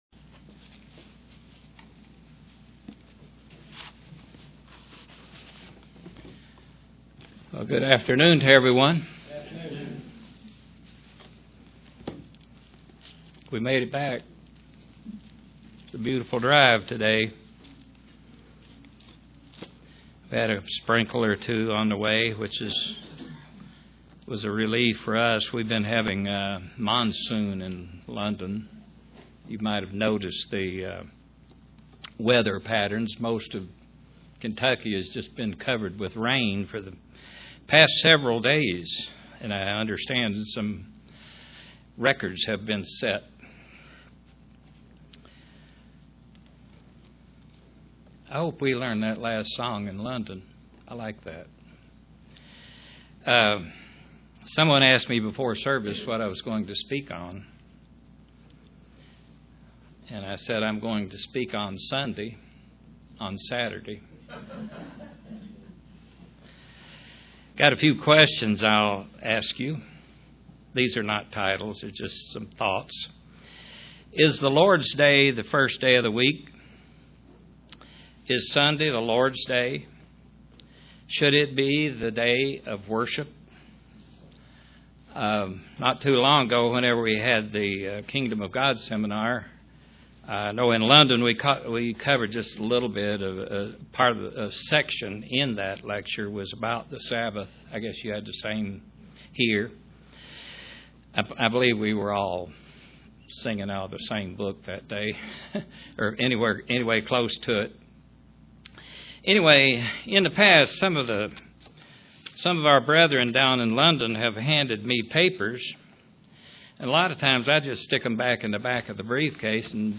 Print Proof that Saturday is the Sabbath UCG Sermon Studying the bible?